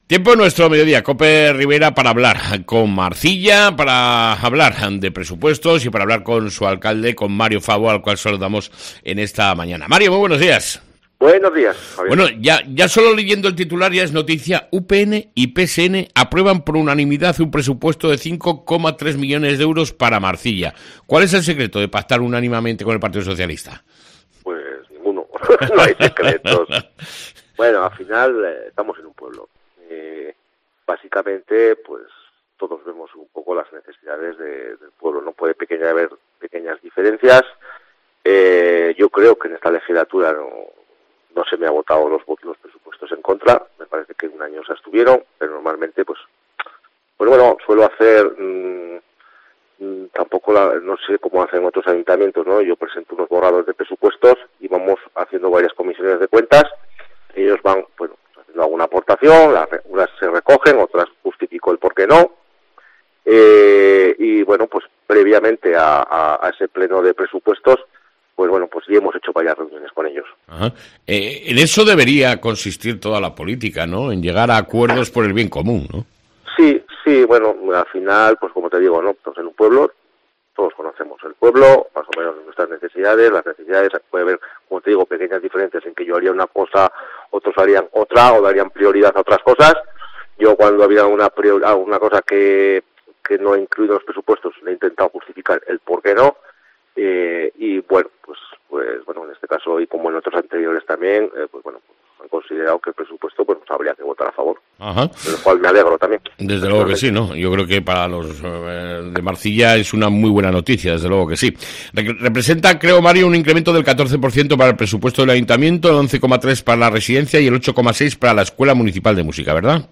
ENTREVISTA CON MARIO FABO, ALCALDE DE MARCILLA